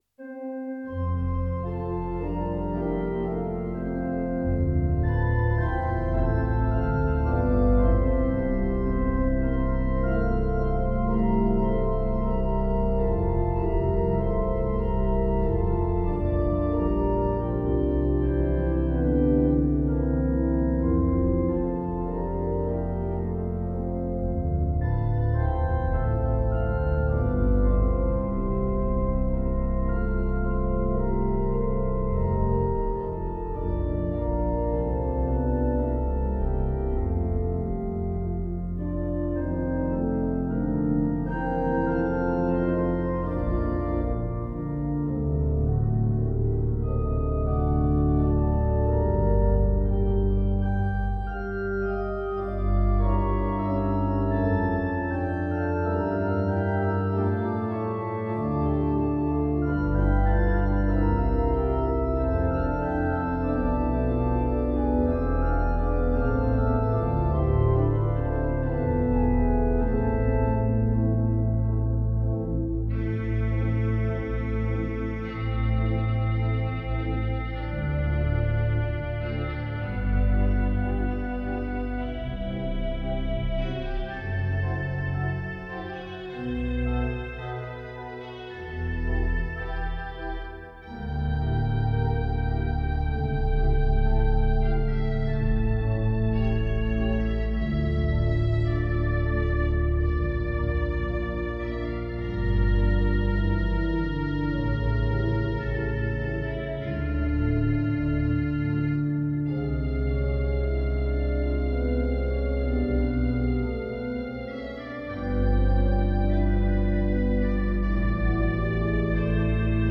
Dit Communion opent met een koraalachtige melodie. In maat 29 ontstaat een nieuw thema met een uitkomende stem. Deze mondt in maat 53 uit in liggende akkoorden met een stappende pedaalpartij, om vervolgens weer terug te keren naar het openingskoraal.